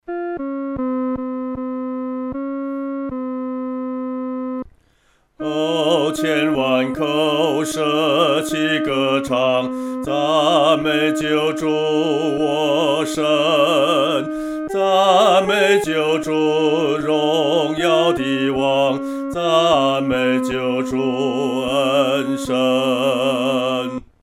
独唱（第二声）
万口欢唱-独唱（第二声）.mp3